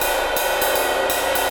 Jazz Swing #2 80 BPM.wav